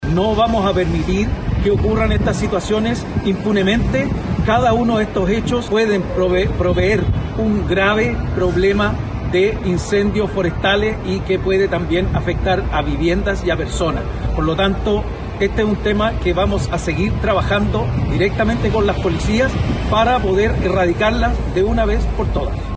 Por otro lado, el Delegado Presidencial Provincial de Marga Marga, Fidel Cueto, declaró que no dejarán que estas situaciones queden en la impunidad.